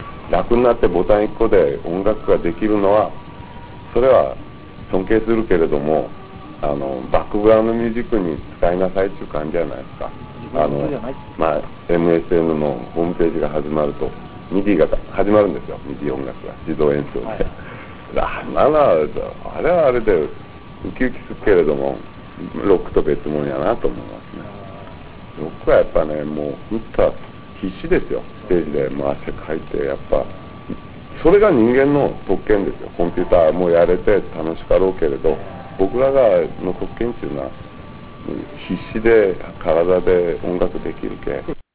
鮎川氏のナマの声を聴けるチャンスです。